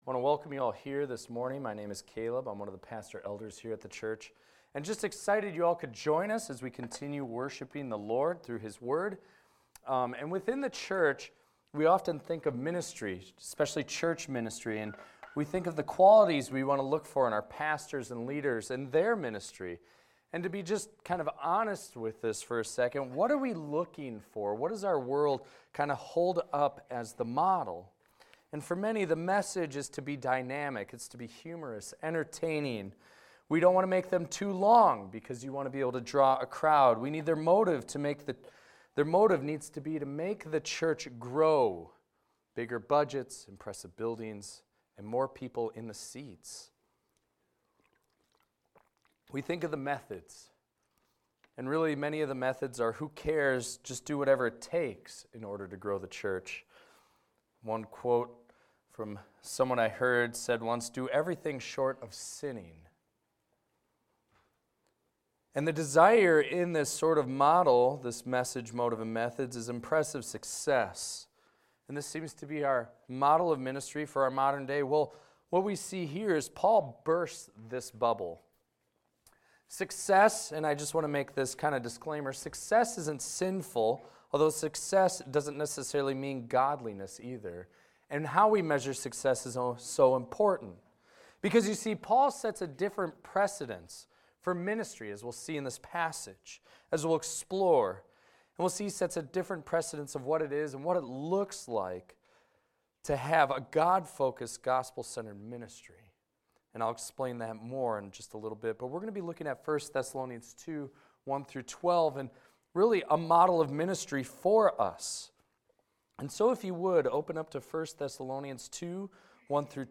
This is a recording of a sermon titled, "Masterclass on Mission."